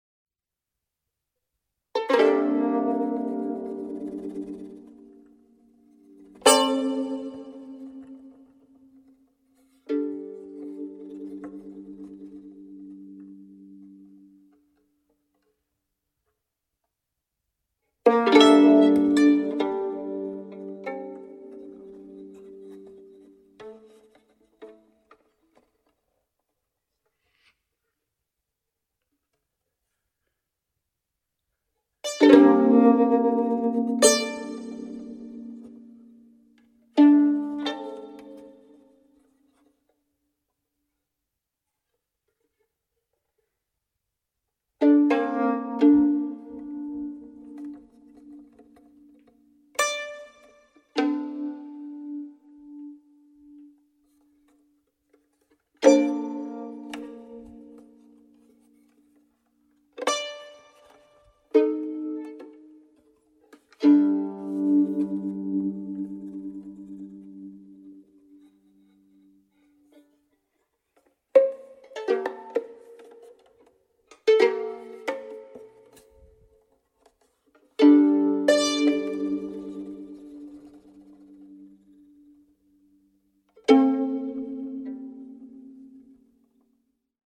violin duo